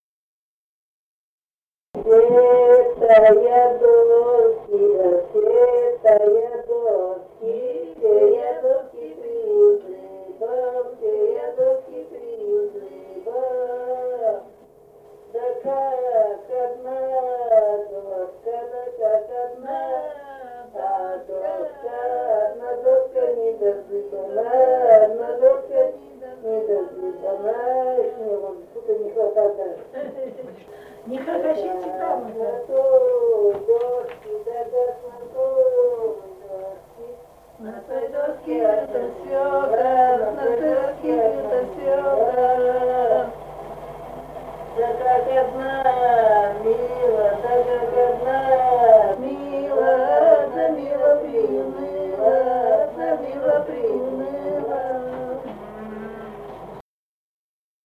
Русские песни Алтайского Беловодья 2 «Все-то я доски приузыбал», «лужошная».
Республика Казахстан, Восточно-Казахстанская обл., Катон-Карагайский р-н, с. Белое, июль 1978.